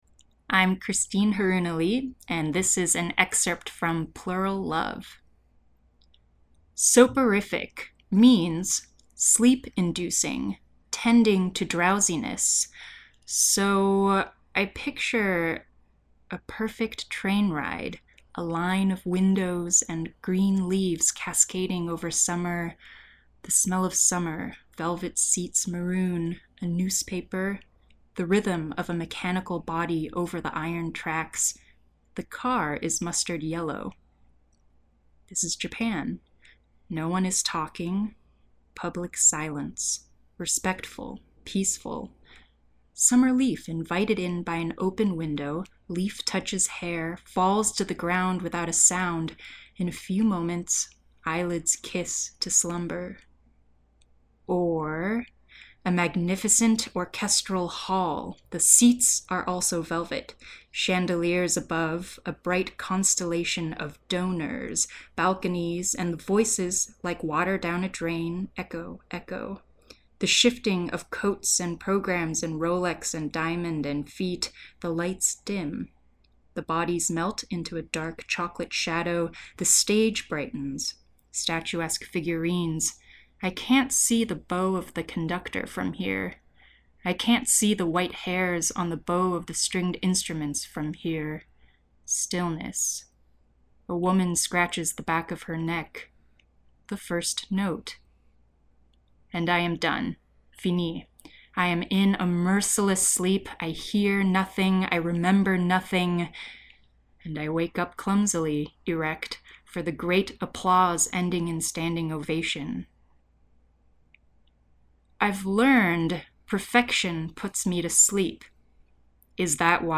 Sound design